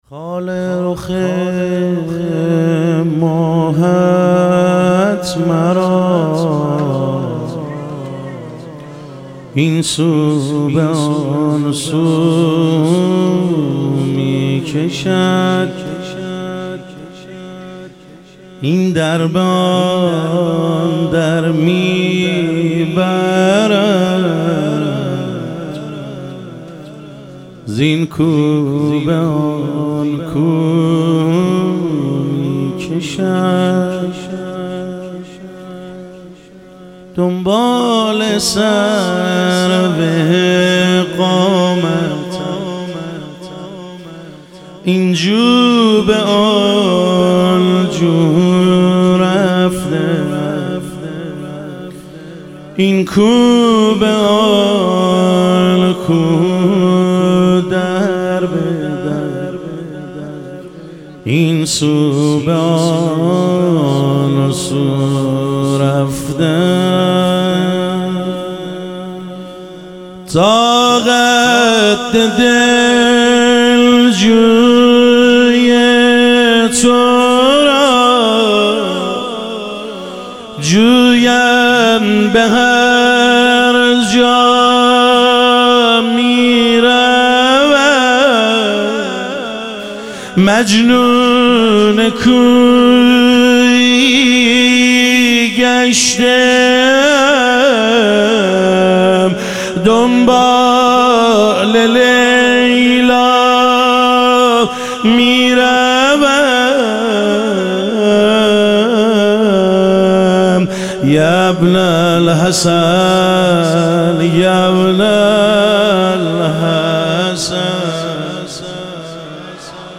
مدح و رجز
شب ظهور وجود مقدس حضرت مهدی علیه السلام